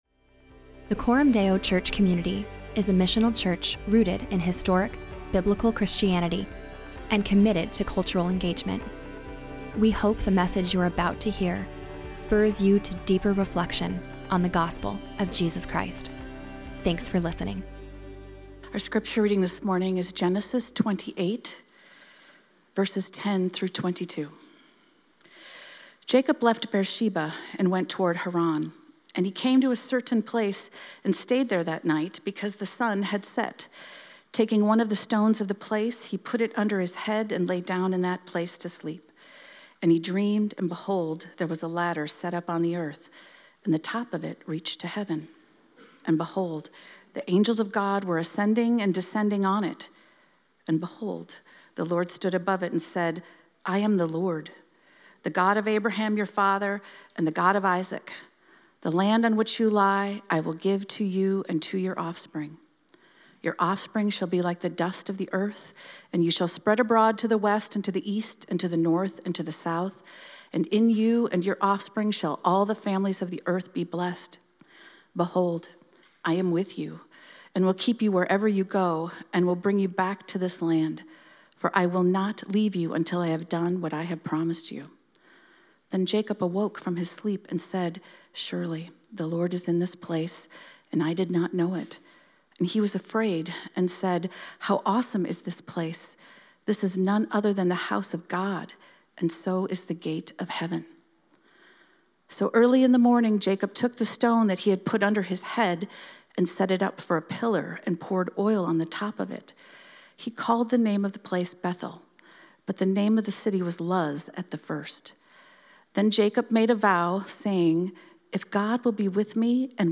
Weekly sermons from Coram Deo Church in Omaha, NE.